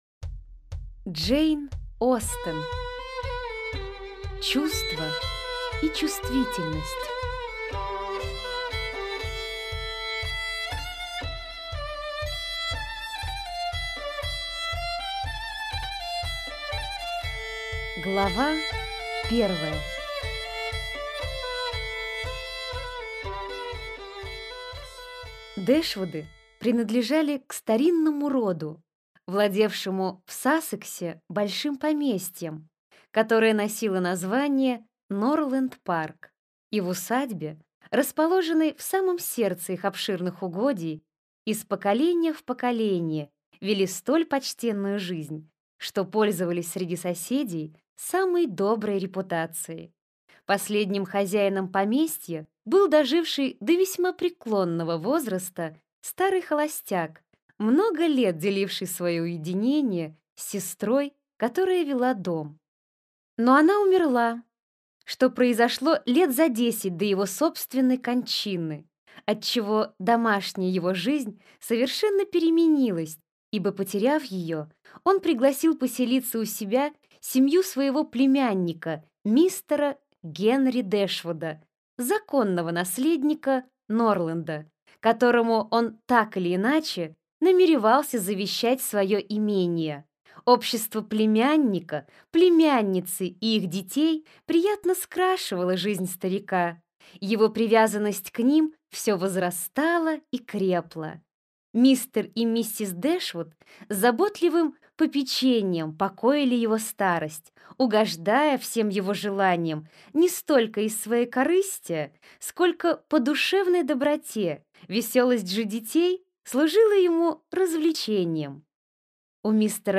Аудиокнига Чувство и чувствительность | Библиотека аудиокниг